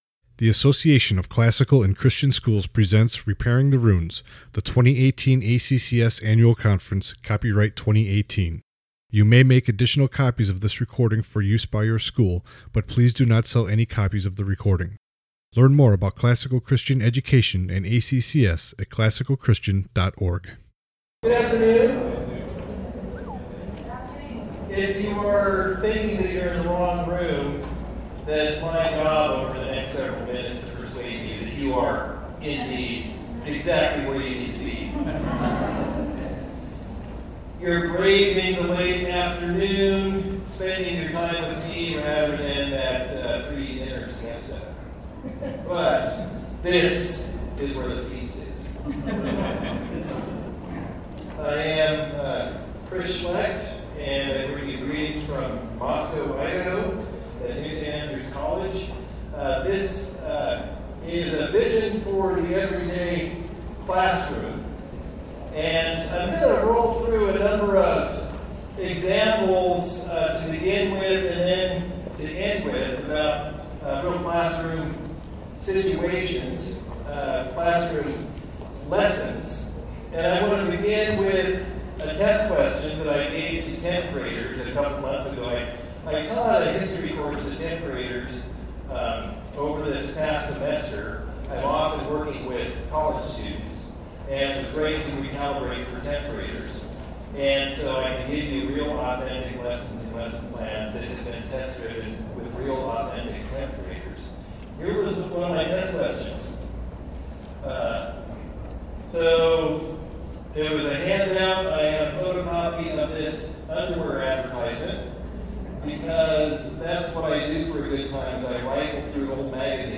2018 Foundations Talk | 59:26 | All Grade Levels, General Classroom